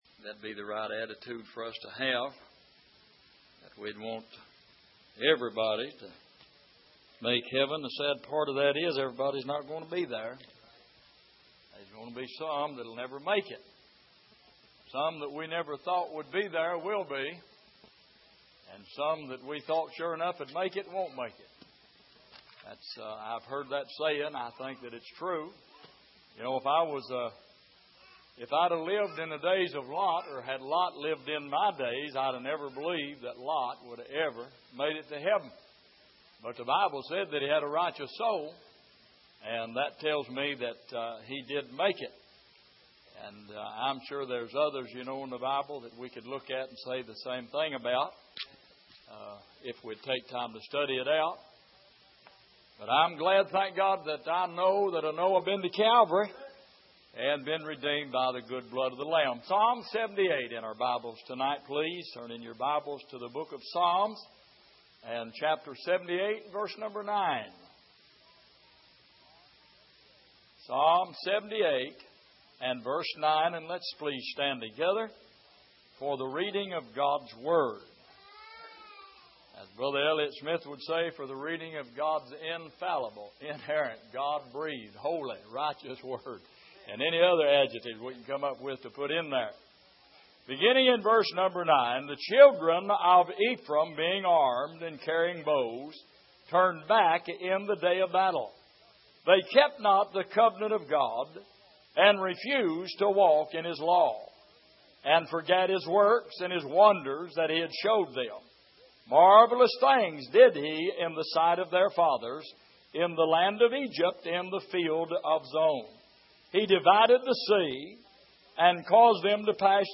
Passage: Psalm 78:9-32 Service: Sunday Evening